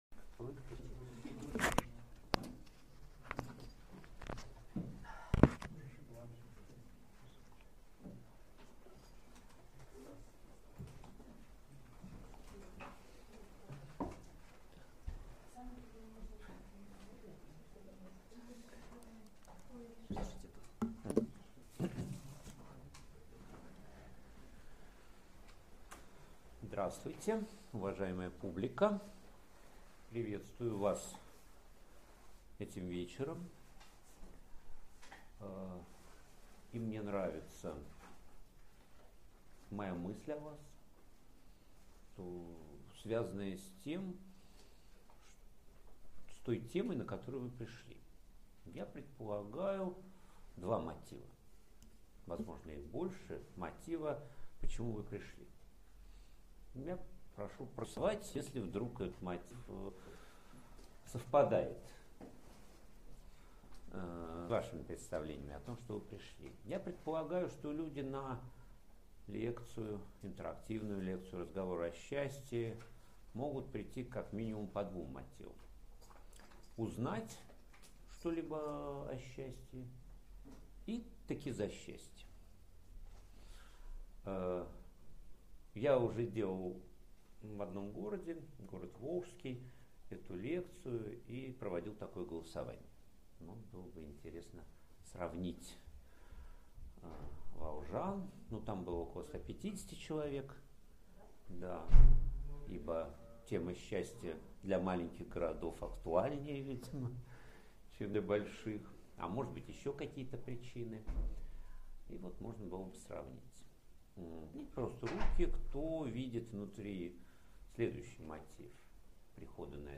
Аудиокнига Как стать счастливым: философский подход | Библиотека аудиокниг